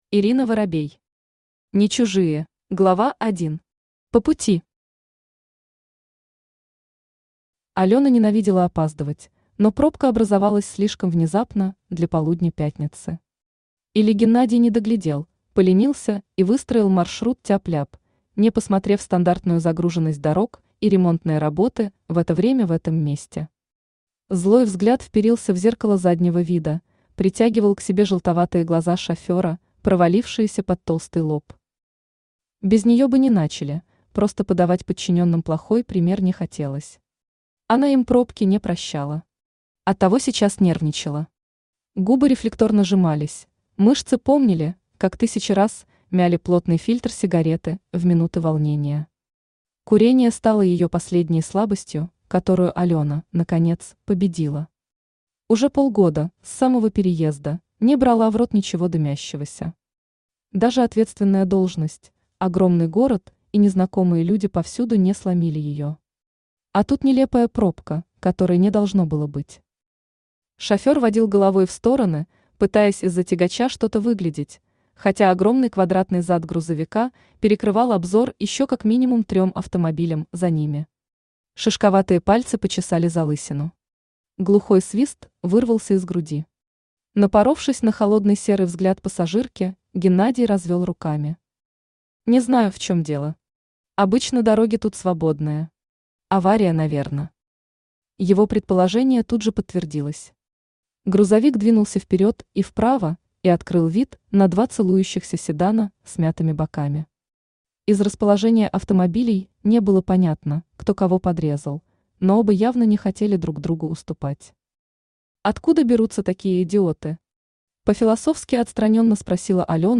Аудиокнига По пути | Библиотека аудиокниг
Aудиокнига По пути Автор Ирина Воробей Читает аудиокнигу Авточтец ЛитРес.